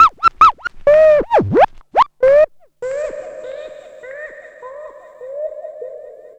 05_Scratchy_150.wav